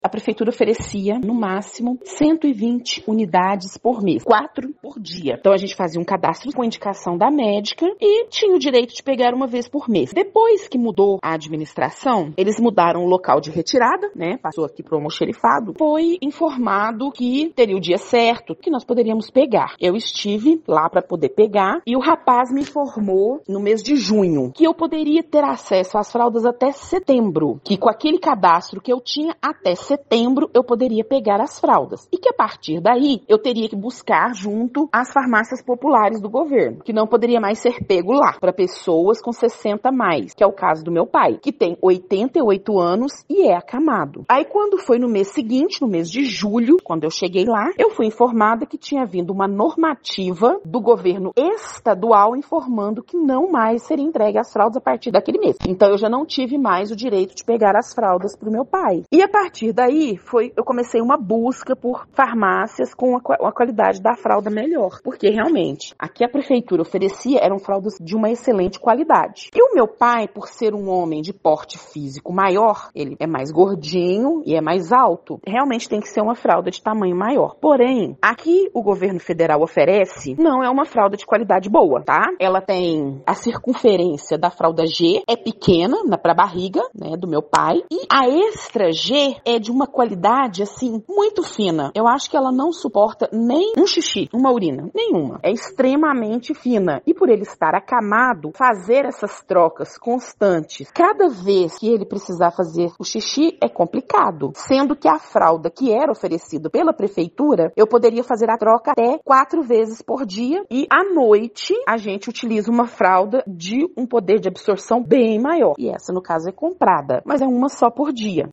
Cuidadora do pai